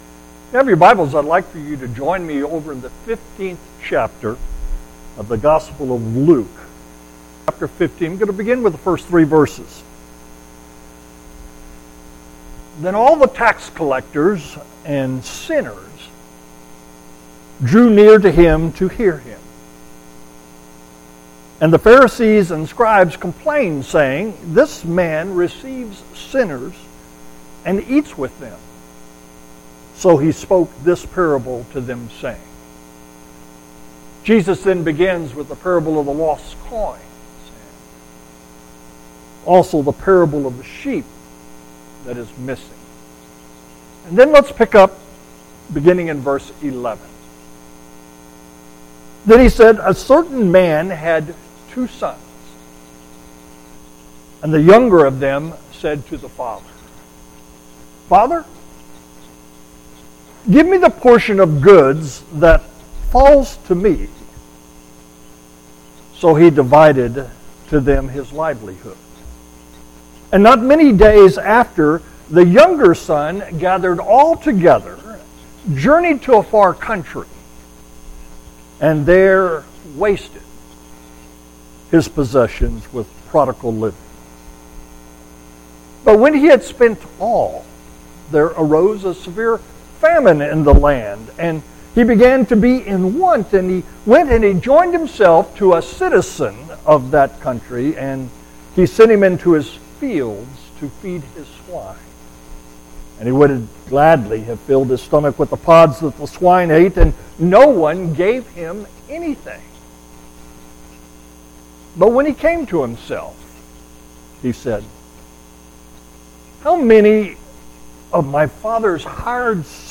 Audio Sermons My Younger Brother Gets Away With Everything!